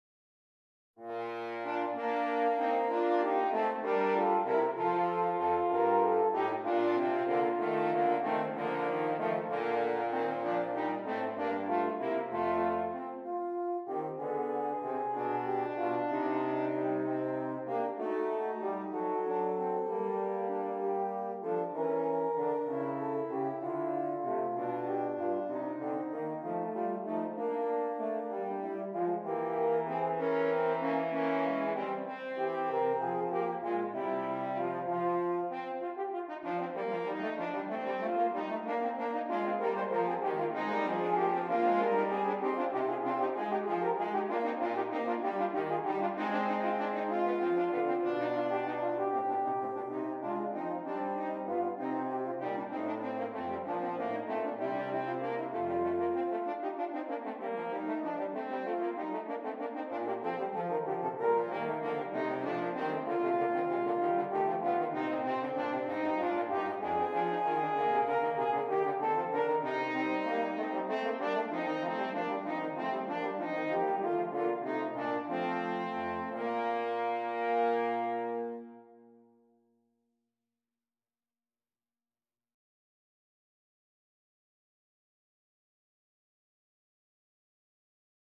3 F Horns
Written for horn trio